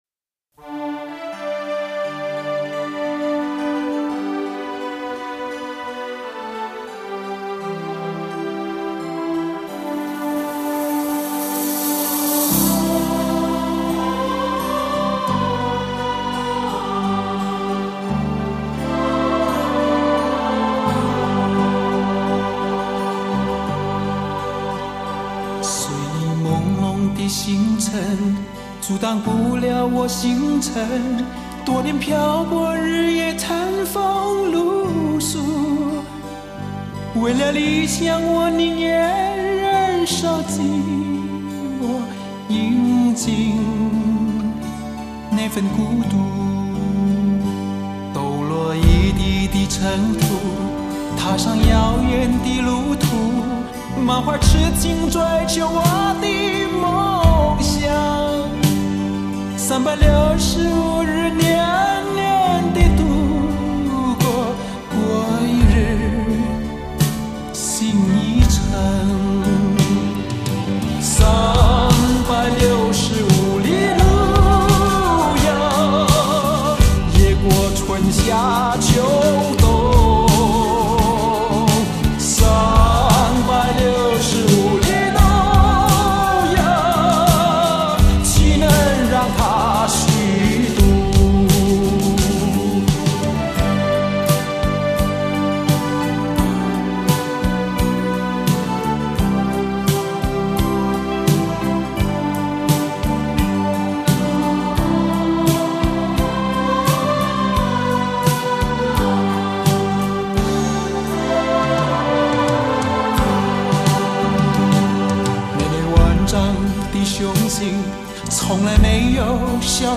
在那富有穿透力又回肠荡气的歌声中，你会听到家乡的